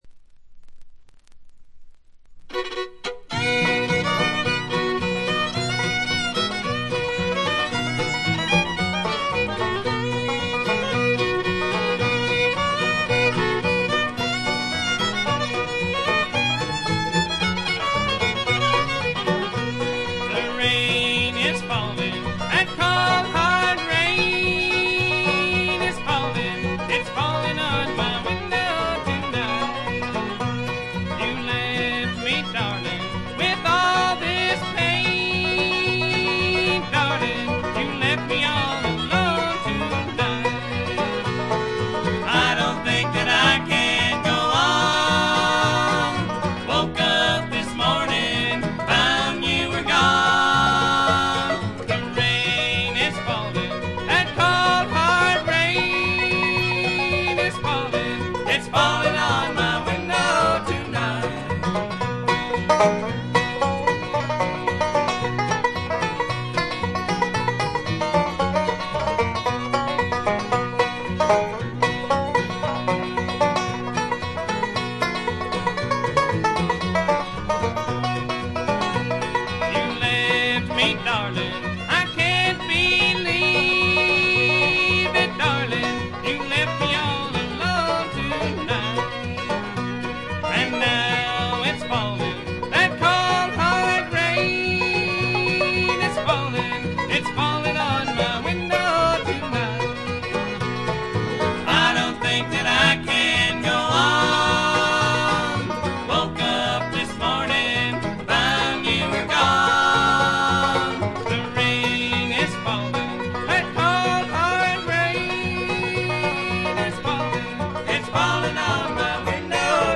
ところどころでチリプチ、散発的なプツ音が少し。
サンフランシスコ近郊オークランドのブルーグラス・バンド。
試聴曲は現品からの取り込み音源です。
Guitar, Lead Vocals, Tenor Vocals, Bass
Mandolin, Lead Vocals, Baritone Vocals
Banjo
Fiddle, Mandolin, Tenor Vocals